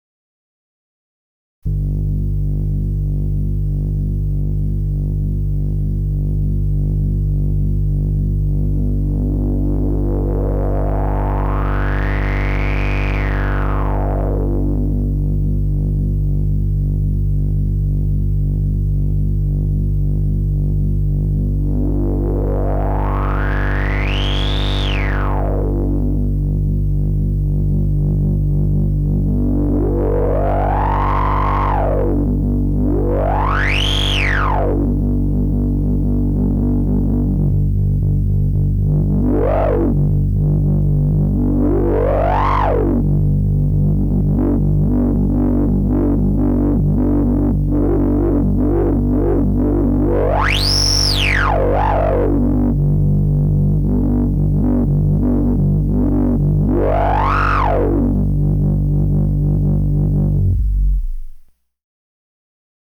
Korg Monotron Duo!